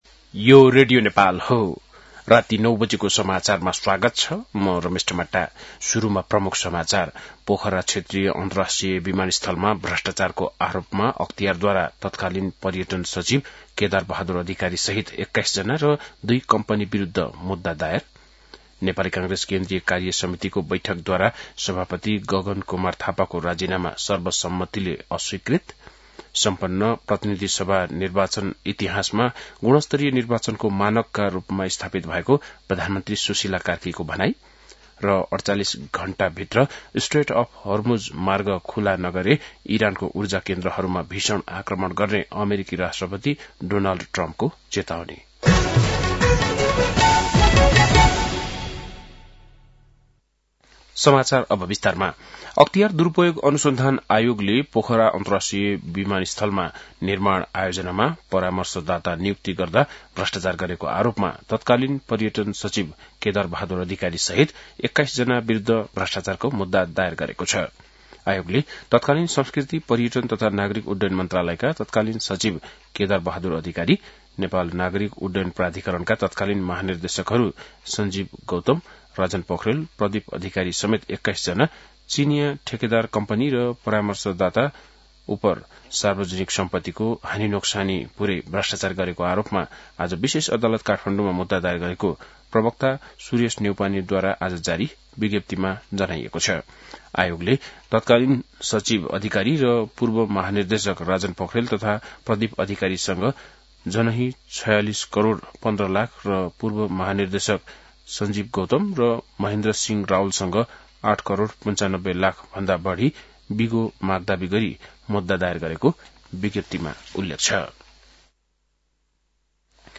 बेलुकी ९ बजेको नेपाली समाचार : ८ चैत , २०८२
9-pm-nepali-news-1-2.mp3